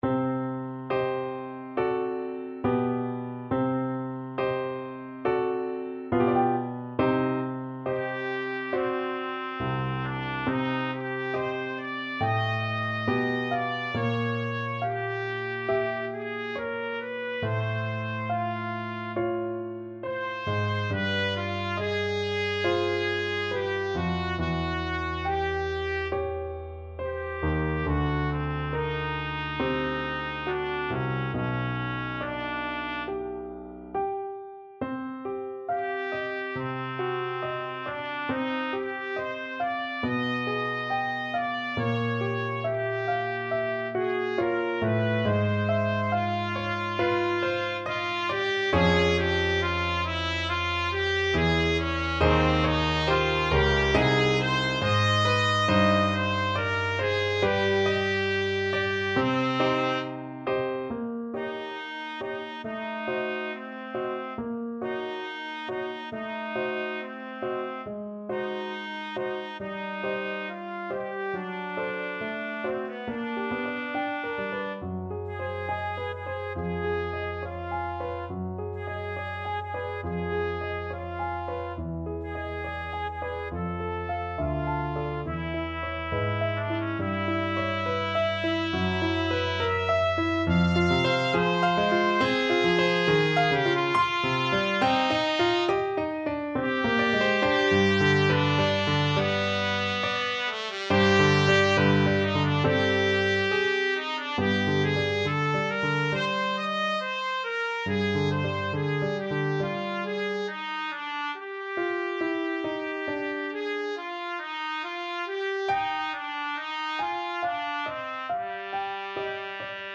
Trumpet version
4/4 (View more 4/4 Music)
~ = 69 Andante con duolo
Classical (View more Classical Trumpet Music)